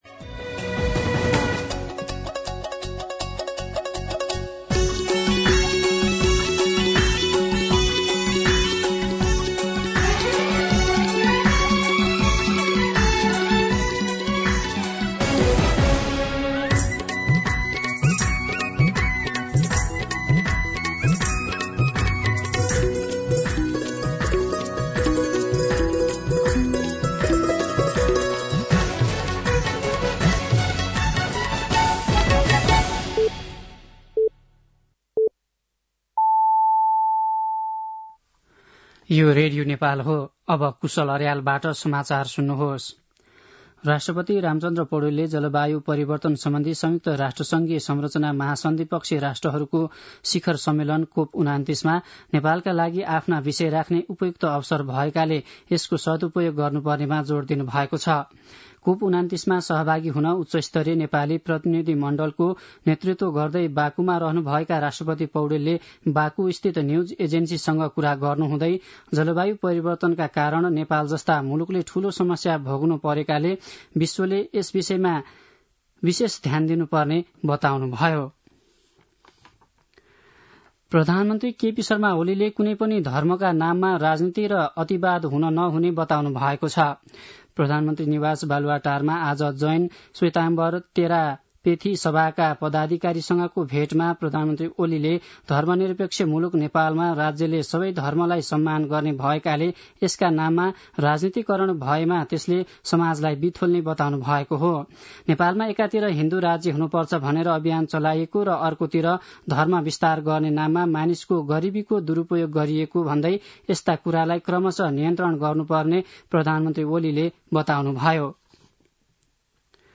दिउँसो ४ बजेको नेपाली समाचार : २८ कार्तिक , २०८१
4-pm-News.mp3